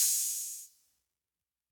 MB Open Hat (6).wav